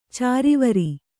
♪ cārivari